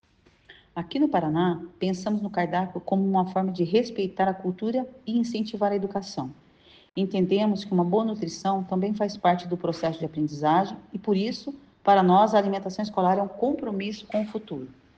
Sonora da diretora-presidente da Fundepar, Eliane Teruel Carmona, os R$ 560 milhões investidos pelo Governo do Estado na alimentação escolar